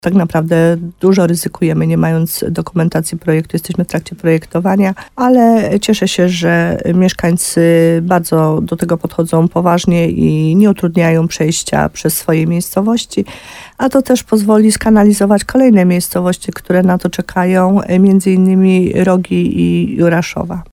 Do końca czerwca musi powstać kanalizacja w Naszacowicach, w gminie Podegrodzie. Samorząd dostał ponad 5 milionów złotych z Program Rozwoju Obszarów Wiejskich. – Pieniądze pochodzą z tzw. rezerwy, więc tempo prac musi być szybkie – mówi wójt Małgorzata Gromala.